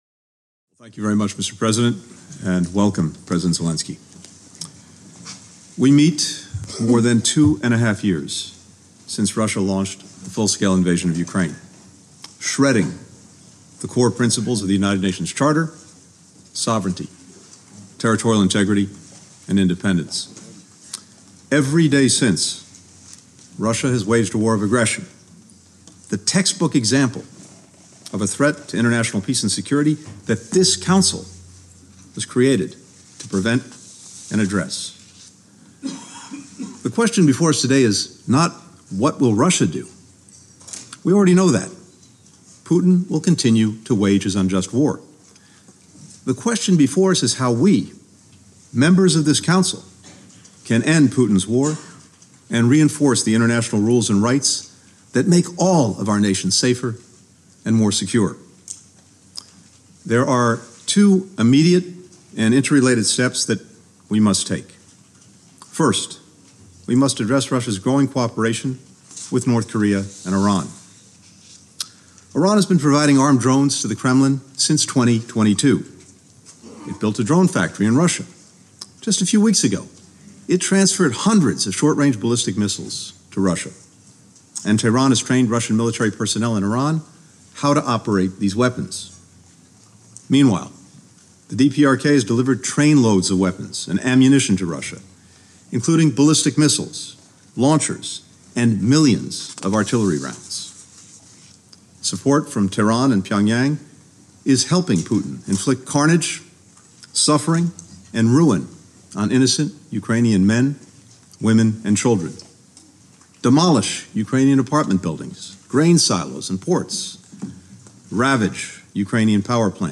Address to a United Nations Ministerial Meeting on Ukraine
delivered 24 September 2024, United Nations HQ, New York, NY